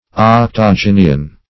Search Result for " octogynian" : The Collaborative International Dictionary of English v.0.48: Octogynian \Oc`to*gyn"i*an\, Octogynous \Oc*tog"y*nous\, a. (Bot.) Having eight pistils; octagynous.